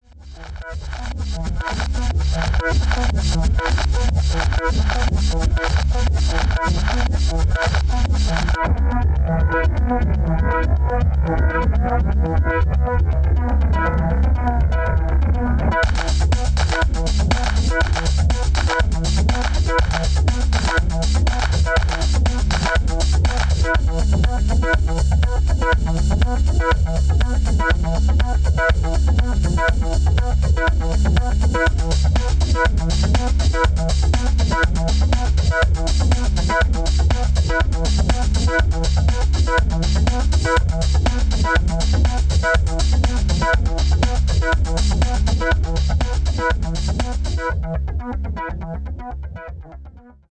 pure Chicago track works